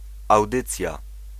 Ääntäminen
IPA: [e.mi.sjɔ̃]